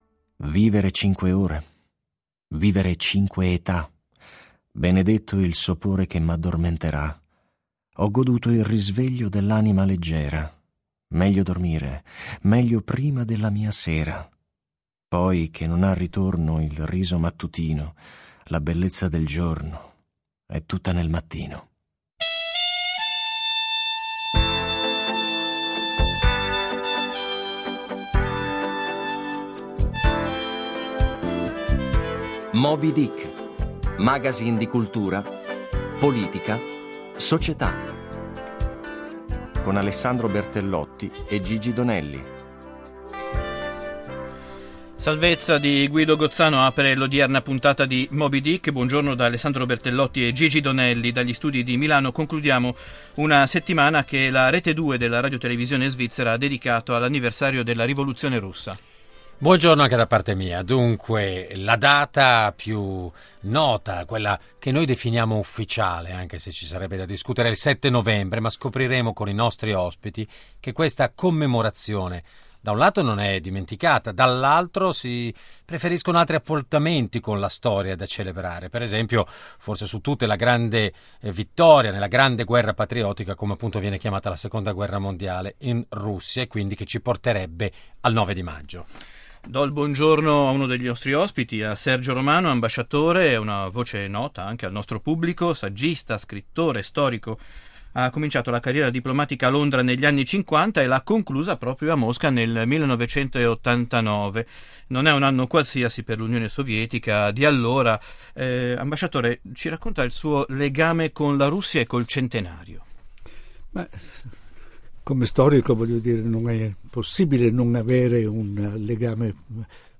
La Russia nuova, la Russia eterna – Interview for Swiss Radio Television, Channel 2